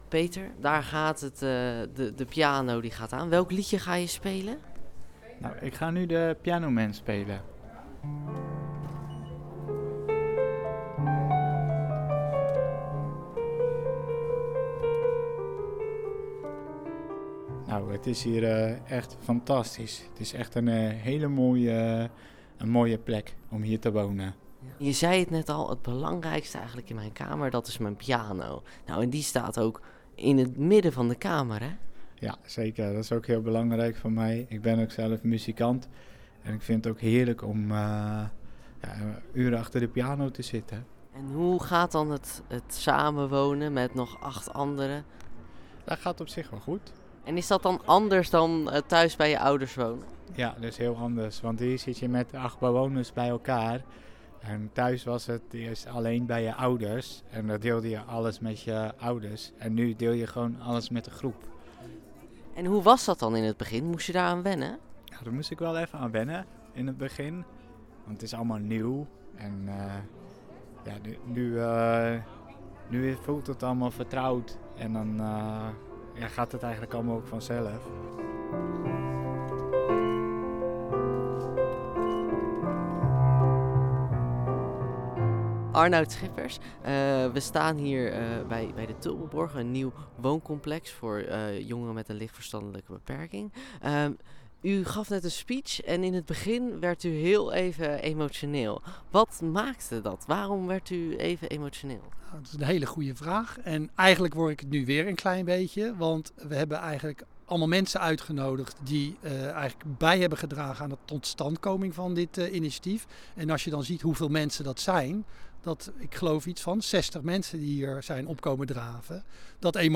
Ook burgemeester Wendy Verkleij was aanwezig bij de opening.